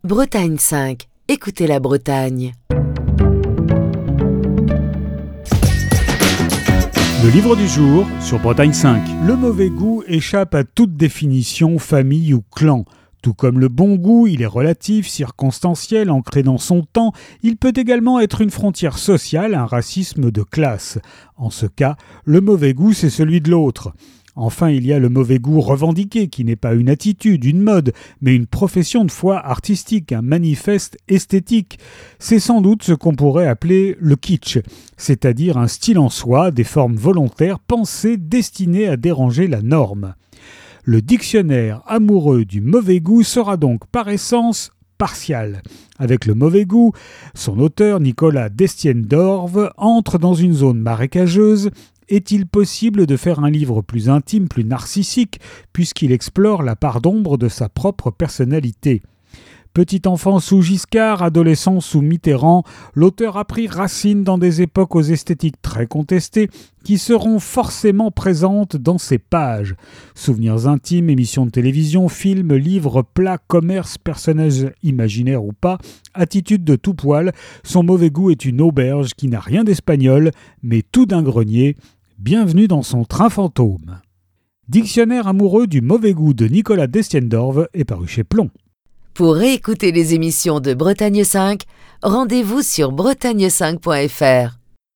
Chronique du 10 février 2023.